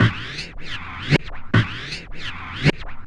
Track 11 - Scratch Beat.wav